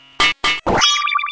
UI_refinefailed.ogg